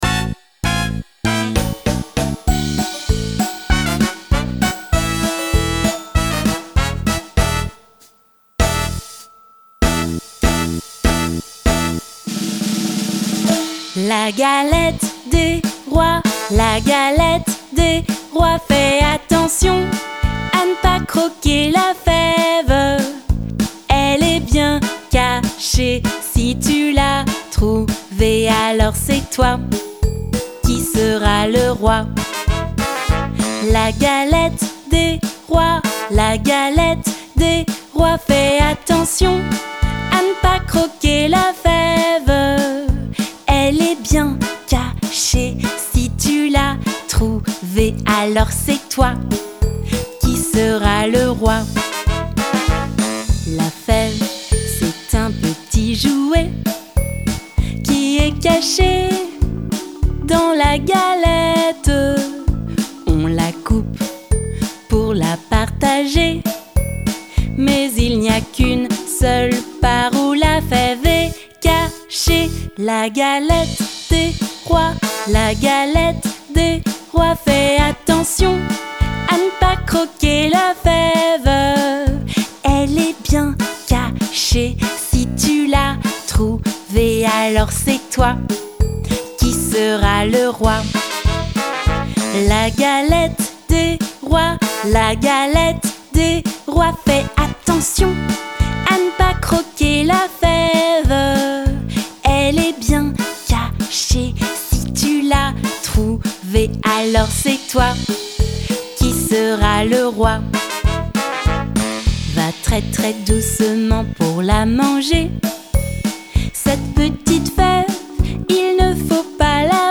This fun original French children’s song